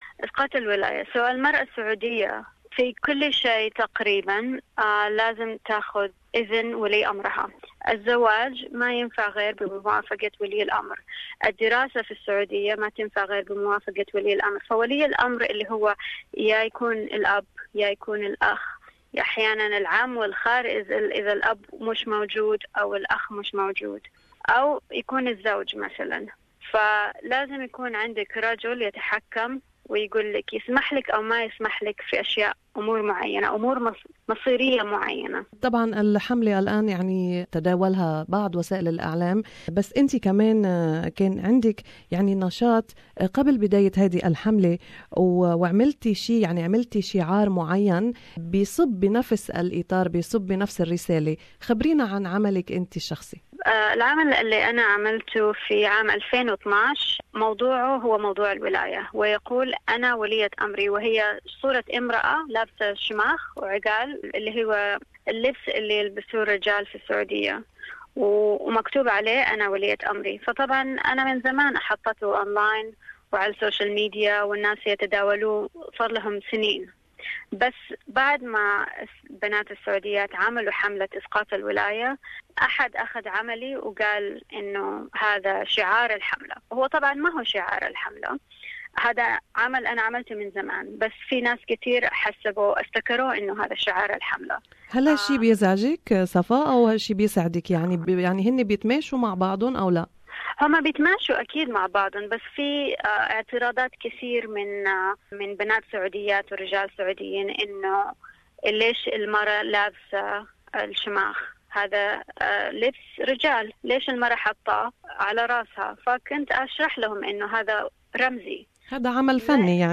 Thousands of Saudis have signed a petition urging an end to the guardianship system giving men control over the work, study, marriage and travel of female relatives, activists this week. Interview